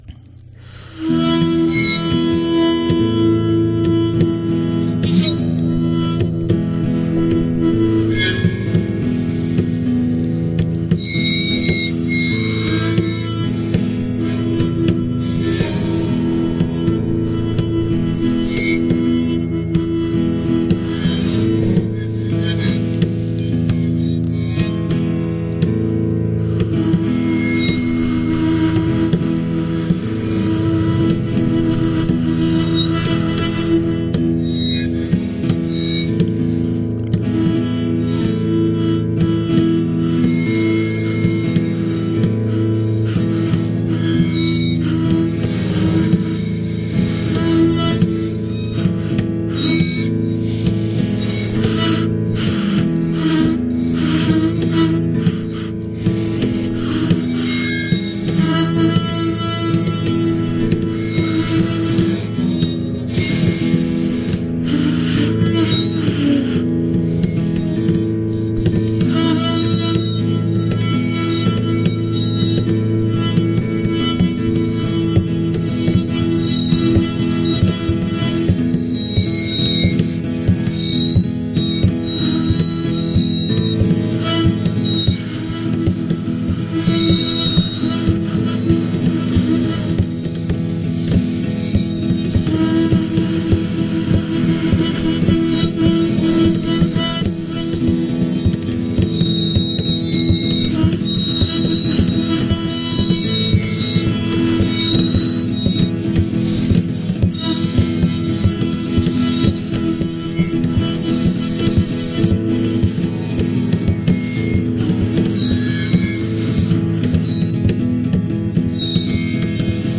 Tag: 吉他 原声 口琴